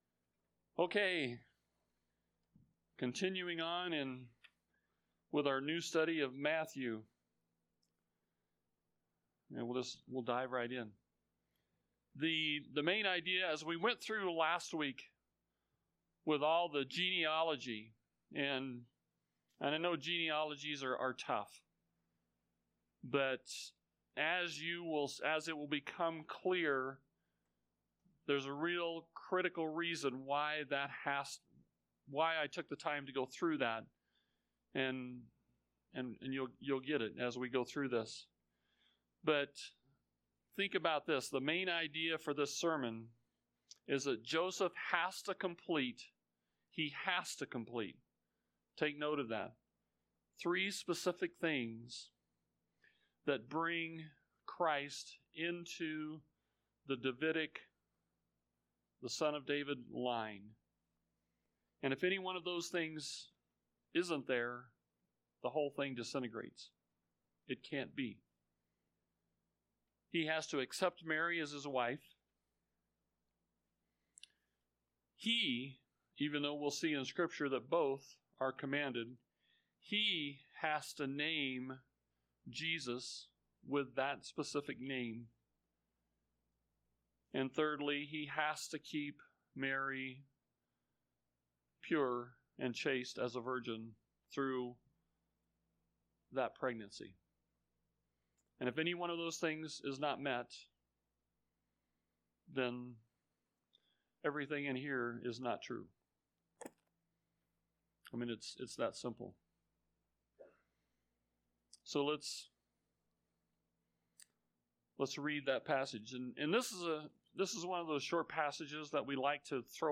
Recent Message